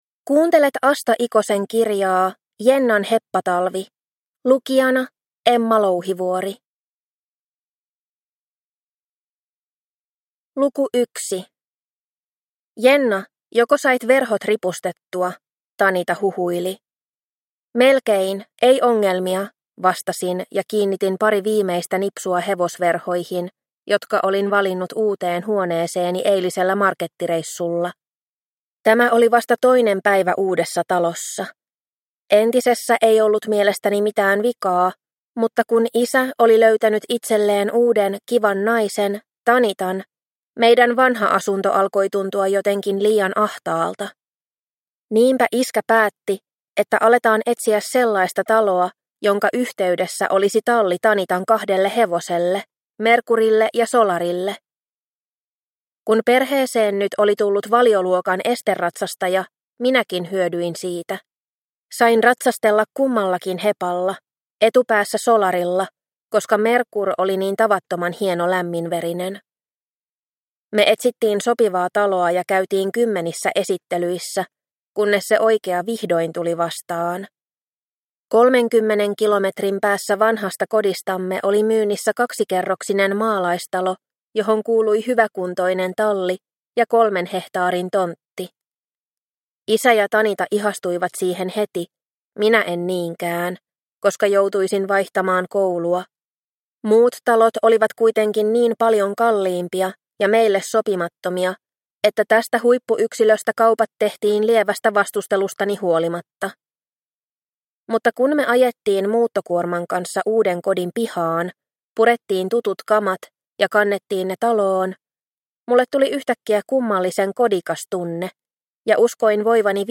Jennan heppatalvi – Ljudbok – Laddas ner